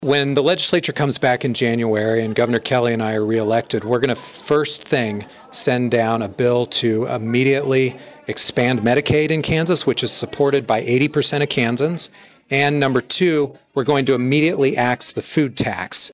During Monday’s event, Toland stated if re-elected the Kelly administration has two immediate pieces of business they’ll look to resolve.